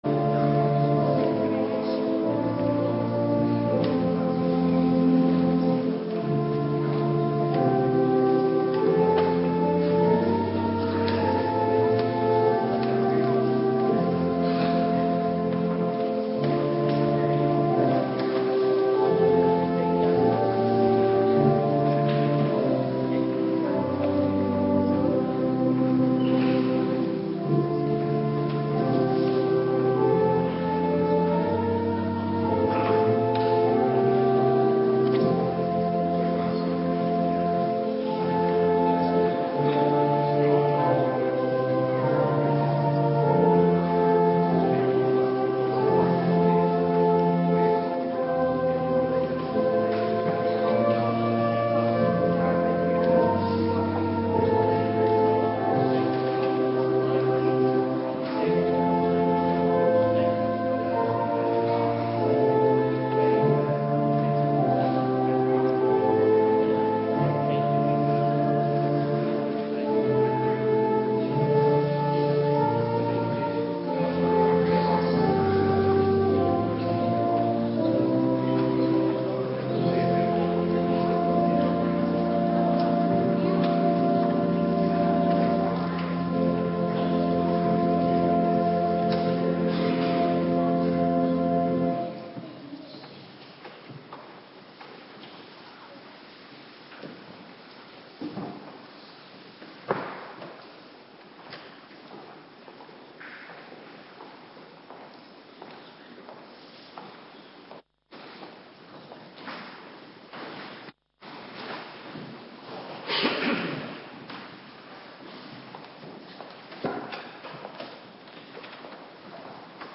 Morgendienst - Cluster 3
Locatie: Hervormde Gemeente Waarder